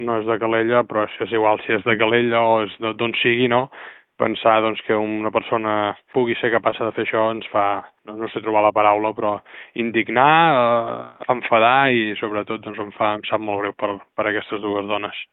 L’alcalde també ha confirmat que el sospitós no és veí de Calella, tot i que ha remarcat que aquest detall és secundari davant la gravetat del cas.